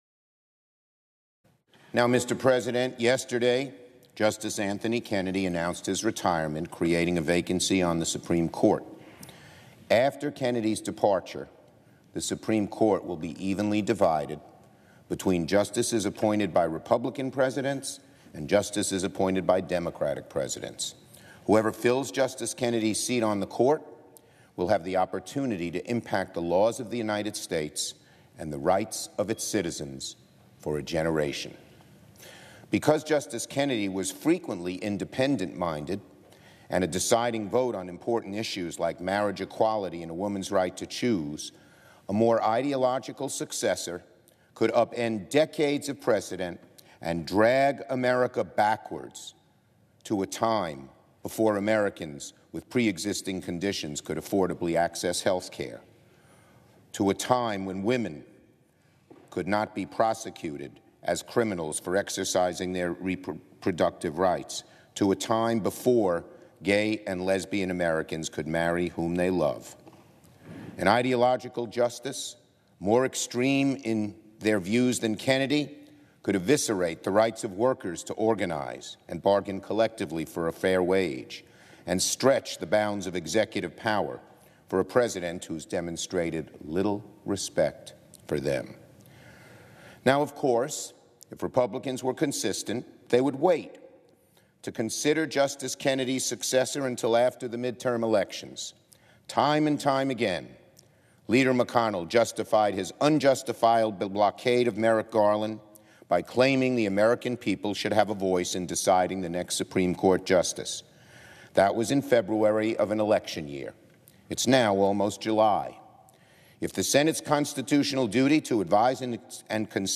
U.S. Senate Minority Leader Chuck Schumer (D-NY) speaks about about the retirement of Supreme Court Justice Anthony Kennedy and his replacement